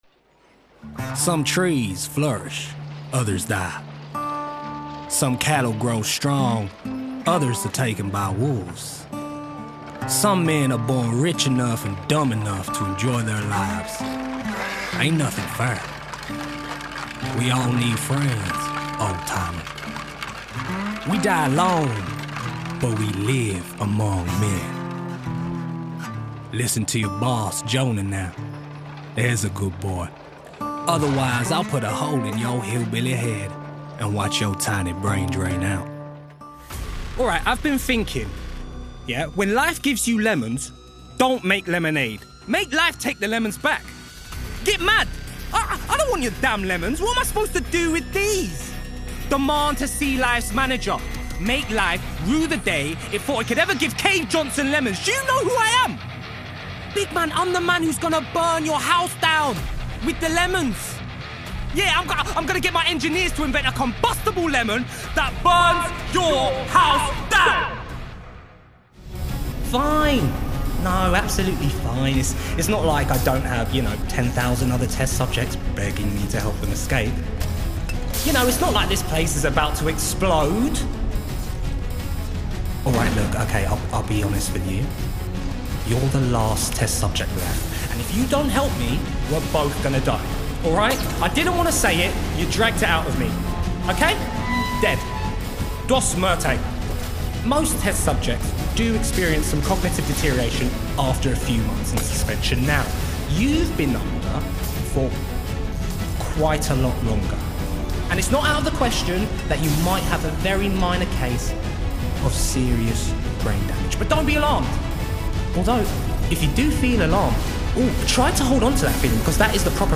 Video Game Reel
• Native Accents: London, Cockney, Caribbean, Jamaican
The ultimate London boy-next-door, he oozes confidence while imbuing any script with an audible smile.